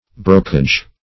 brocage - definition of brocage - synonyms, pronunciation, spelling from Free Dictionary
Search Result for " brocage" : The Collaborative International Dictionary of English v.0.48: Brocage \Bro"cage\, n. See Brokkerage .